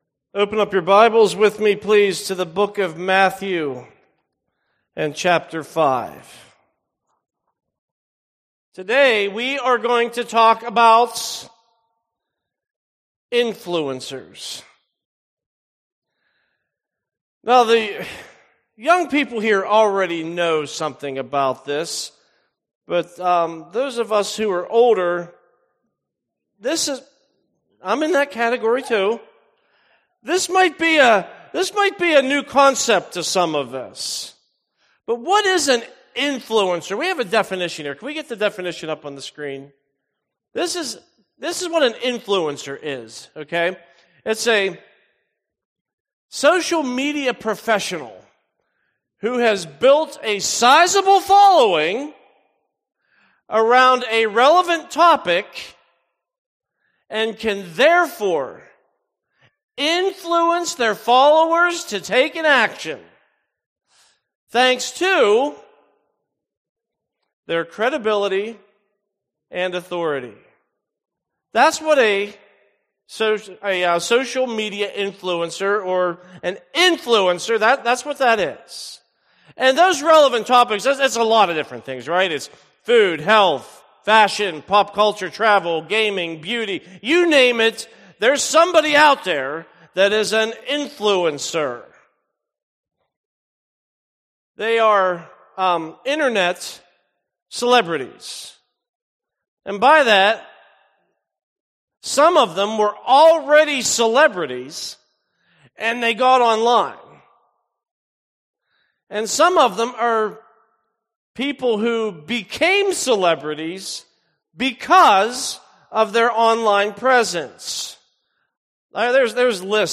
Weekly sermon notes and sermon audio linked with archive and podcast feed.